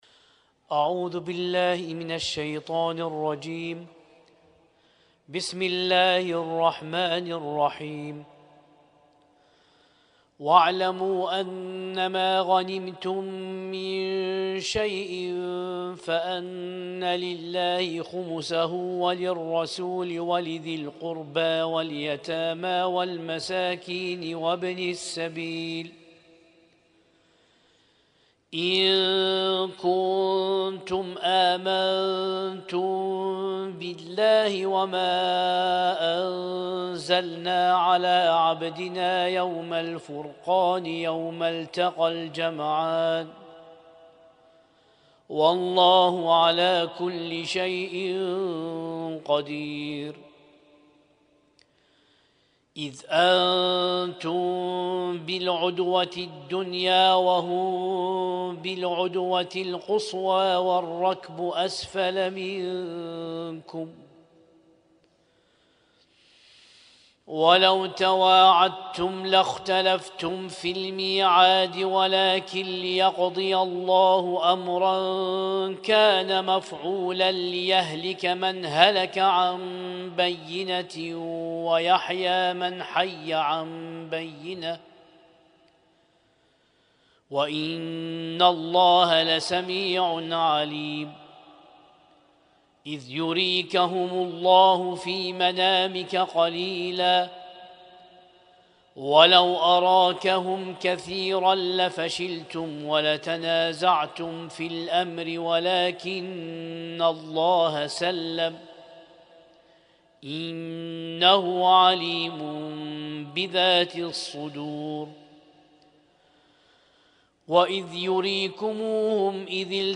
اسم التصنيف: المـكتبة الصــوتيه >> القرآن الكريم >> القرآن الكريم 1447